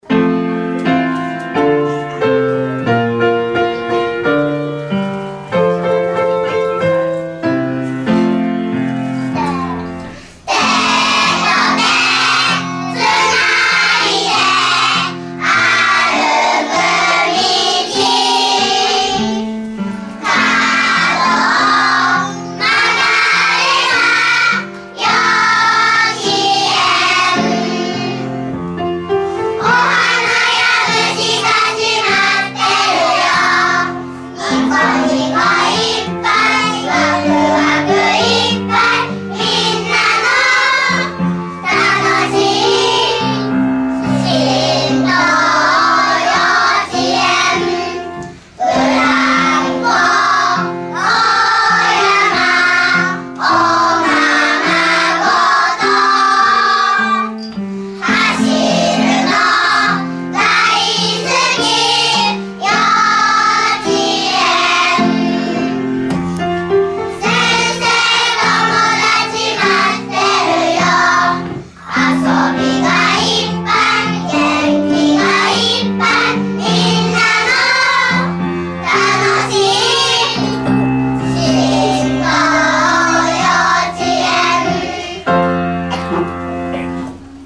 しんとう幼稚園園歌.mp3